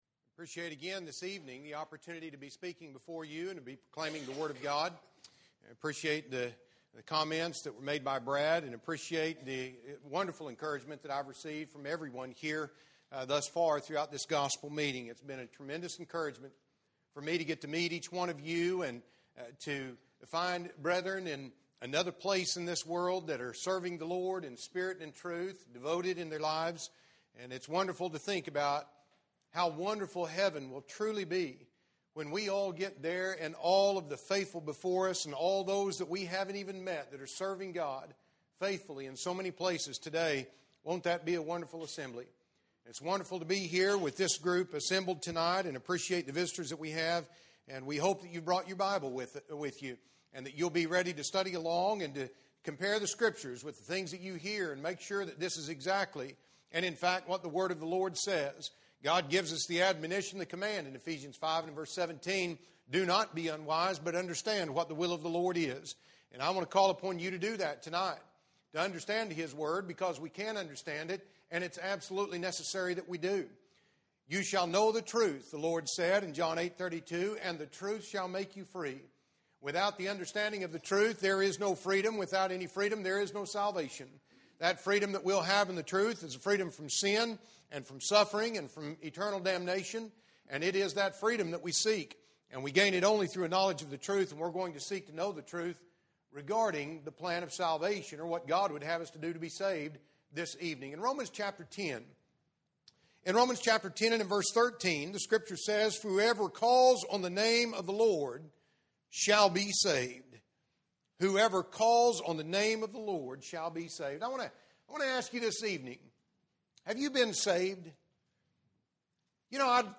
Service Type: Gospel Meeting